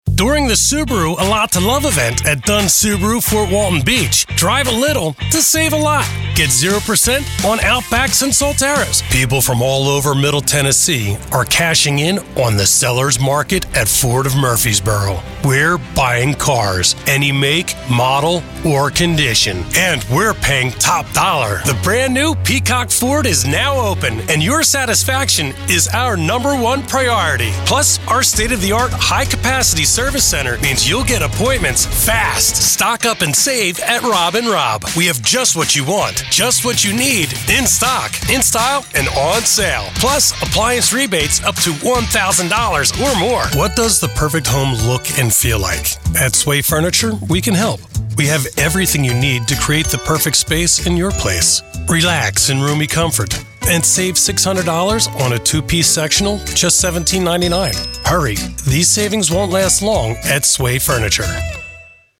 Male
Friendly, middle aged, father, confident, construction worker, professional, conversational, warm, authentic, engaging, deep, authentic, intimate, articulate, guy next door, informed, knowledgeable
Television Spots
Automotive Retails Spots.
Words that describe my voice are confident, middle aged, warm.